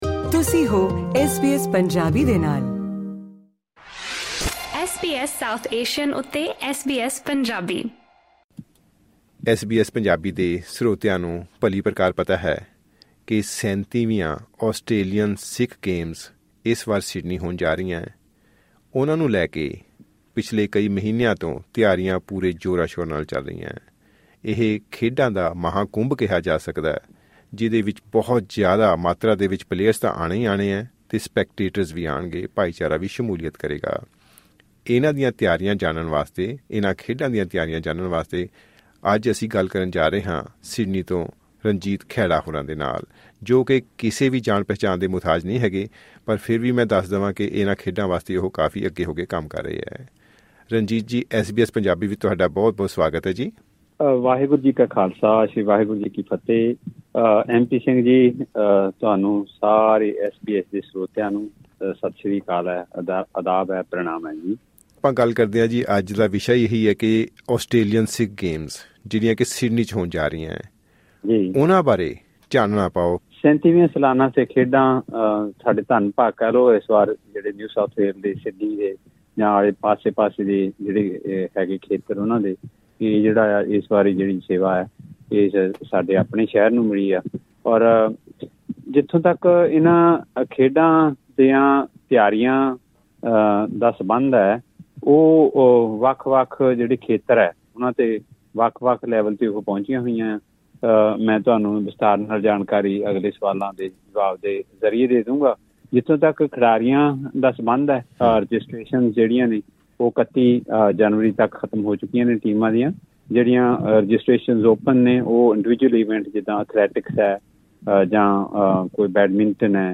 ਖਾਸ ਗੱਲਬਾਤ